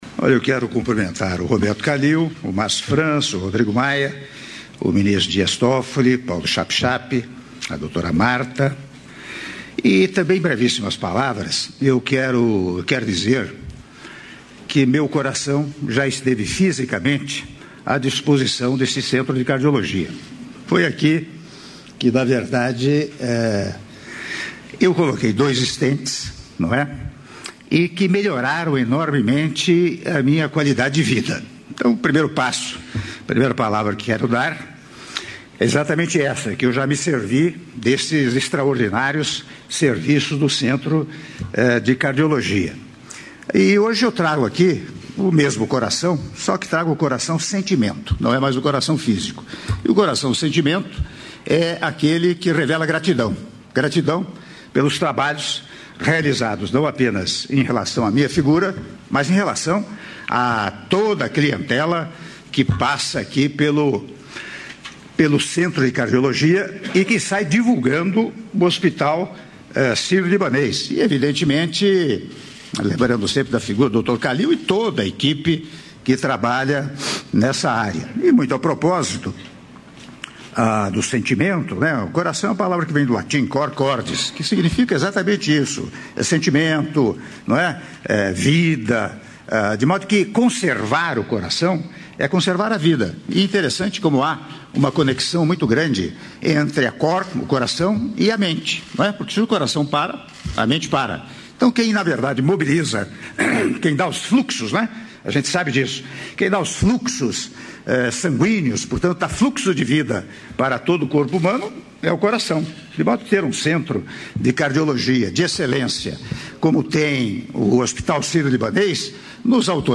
Áudio do discurso do Presidente da República, Michel Temer, durante Cerimônia em comemoração aos 10 anos do centro de cardiologia do Hospital Sírio-Libanês -São Paulo/SP- (03min0s)